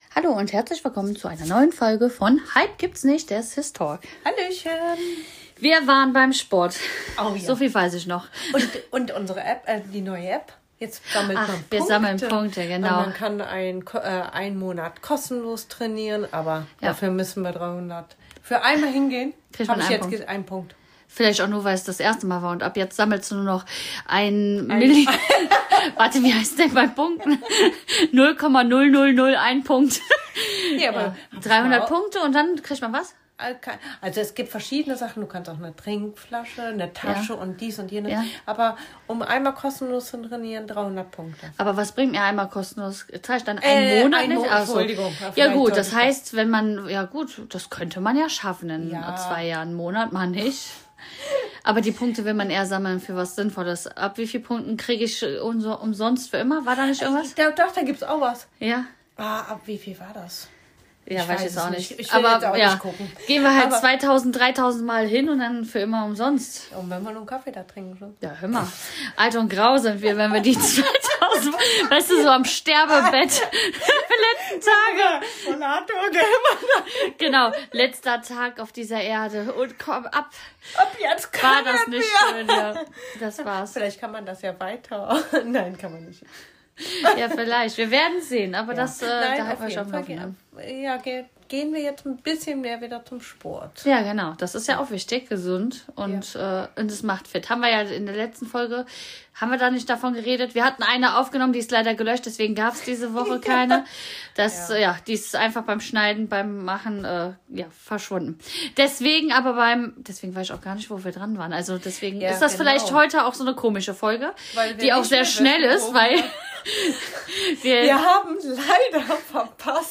Beschreibung vor 5 Monaten In dieser Folge sprechen zwei Schwestern ganz ehrlich über das, was oft unausgesprochen bleibt: Was passiert, wenn der Kopf einfach nie stillsteht? Zwischen Grübeleien, Selbstzweifeln und dem ständigen Gedankenkarussell teilen sie ihre persönlichen Erfahrungen mit mentaler Unruhe, Überforderung und dem Wunsch nach innerer Ruhe. Mit viel Herz, Humor und Offenheit nehmen sie dich mit in ihre Gedankenwelt – dahin, wo’s manchmal laut, aber immer echt ist.